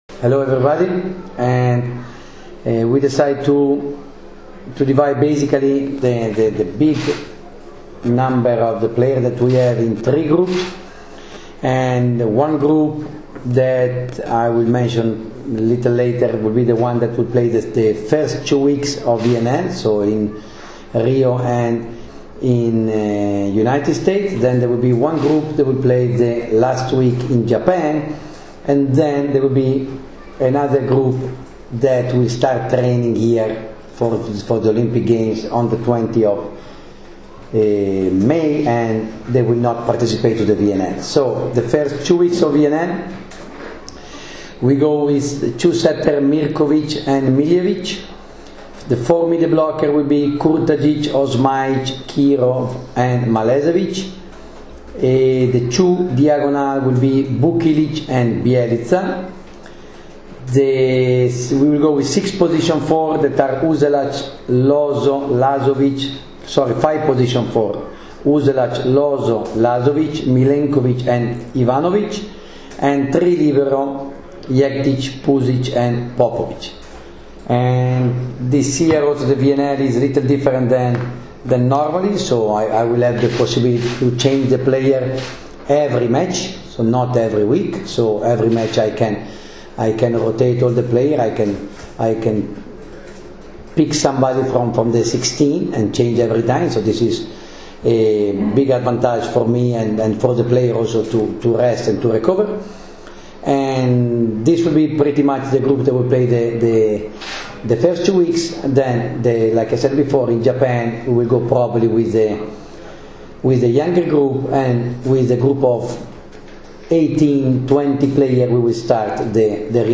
Izjava Đovanija Gvidetija
lz1-gvideti.wma